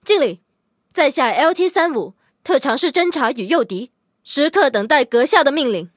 LT-35登场语音.OGG